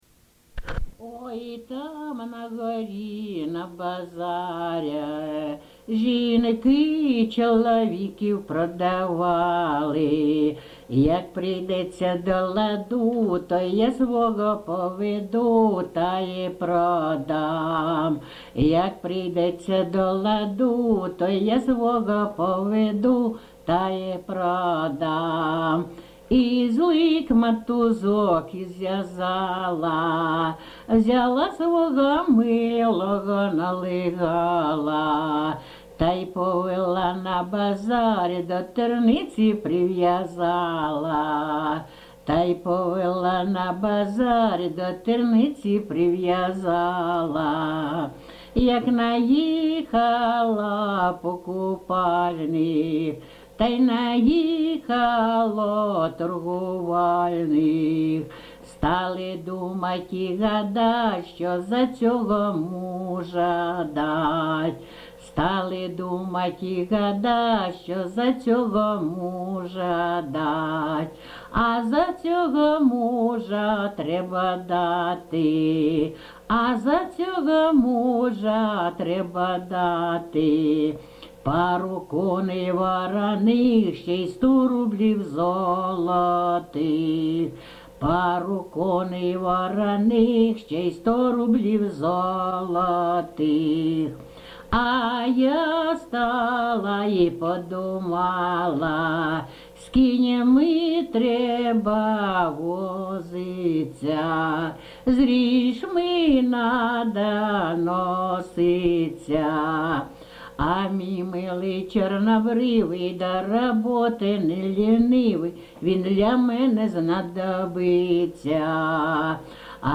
ЖанрЖартівливі
Місце записус. Некременне, Олександрівський (Краматорський) район, Донецька обл., Україна, Слобожанщина